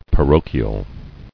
[pa·ro·chi·al]